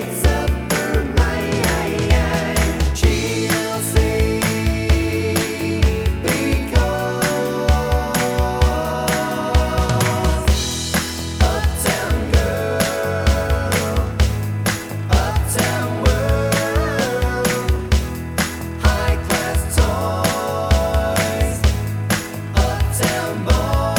Pop (1980s)